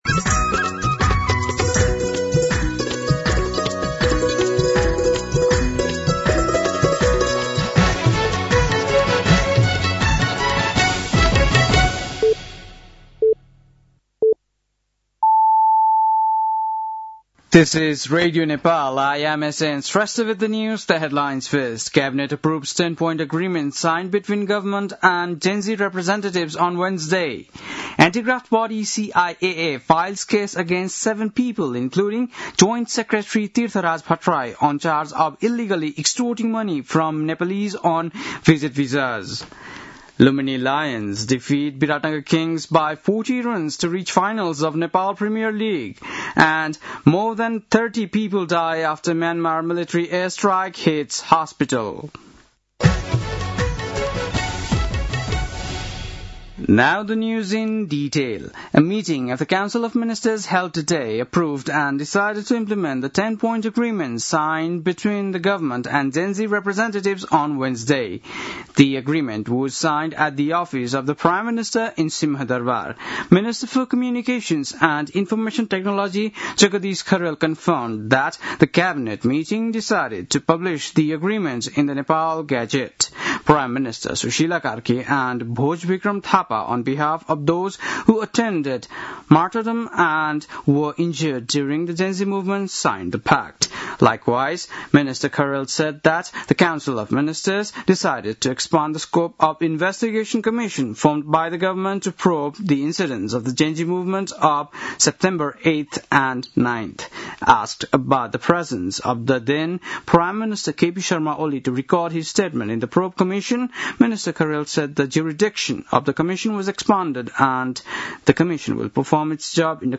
बेलुकी ८ बजेको अङ्ग्रेजी समाचार : २५ मंसिर , २०८२
8-pm-news-8-25.mp3